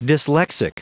Prononciation du mot dyslexic en anglais (fichier audio)
Prononciation du mot : dyslexic